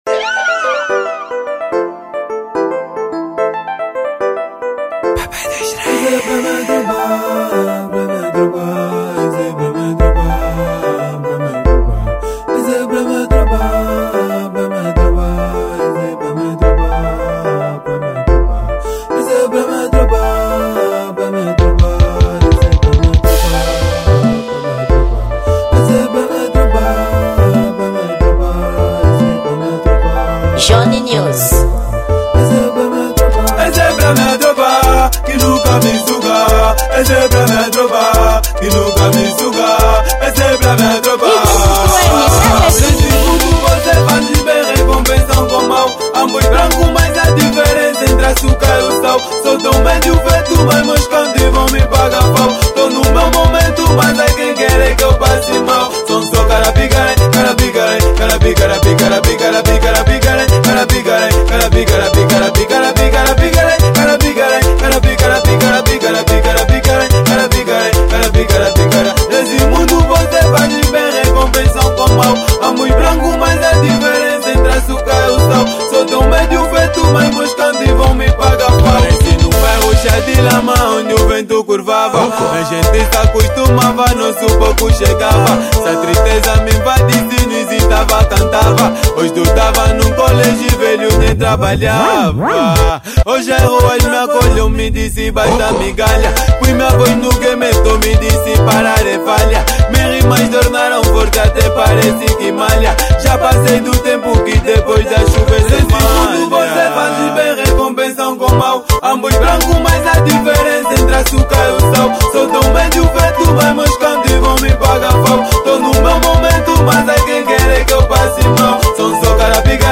Gênero: Kuduro